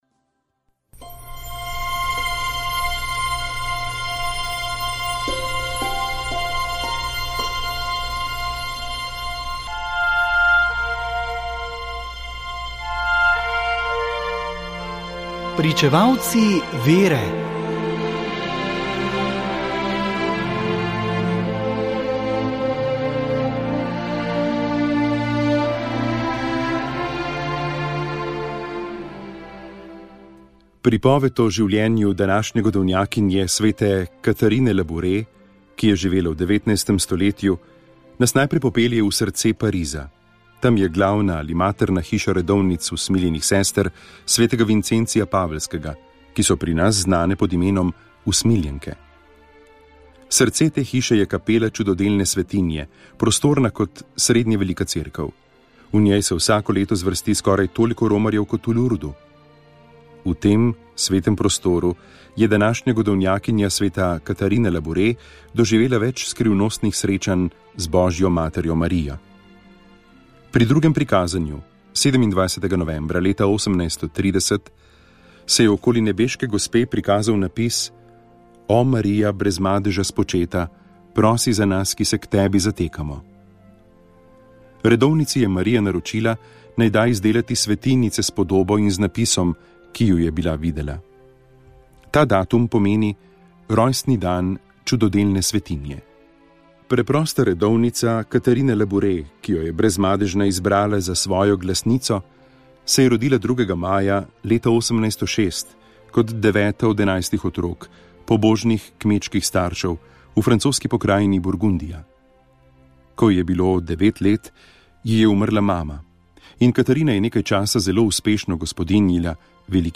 Iz knjige Svetnik za vsak dan Silvestra Čuka se vsak dan na Radiu Ognjišče prebira o svetniku dneva.